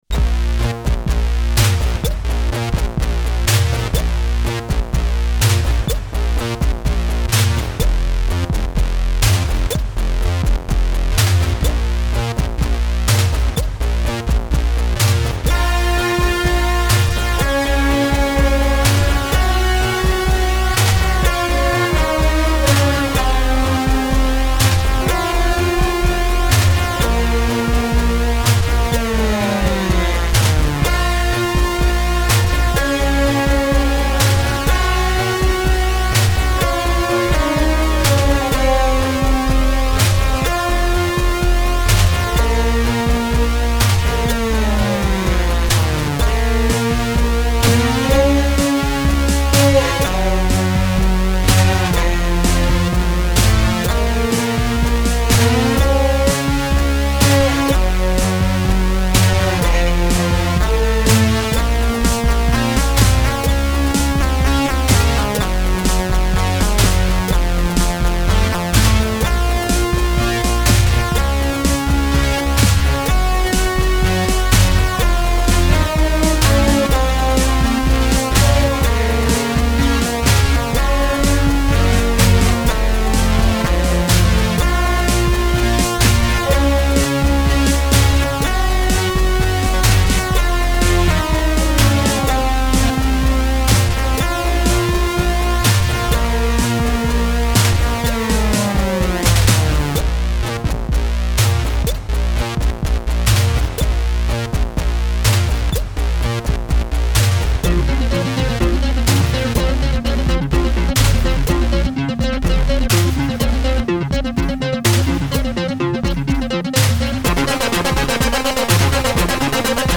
Commodore 64 Remakes and Remixes ---